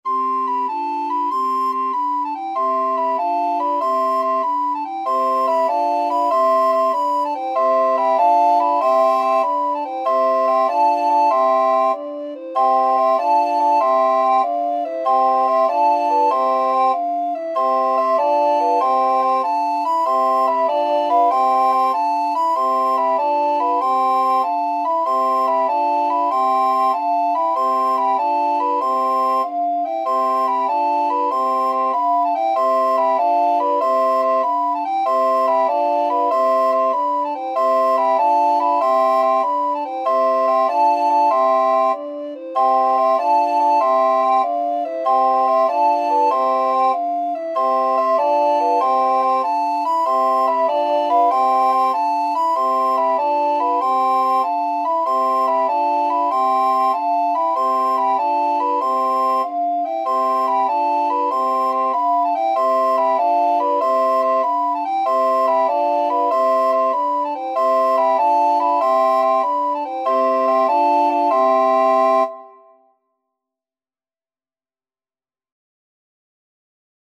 Classical Trad. Sumer is icumen in (Summer is a-coming in) Recorder Ensemble version
Alto Recorder 1Alto Recorder 2Alto Recorder 3Alto Recorder 4Bass Recorder 1Bass Recorder 2
C major (Sounding Pitch) (View more C major Music for Recorder Ensemble )
3/8 (View more 3/8 Music)
Happily .=c.96
Classical (View more Classical Recorder Ensemble Music)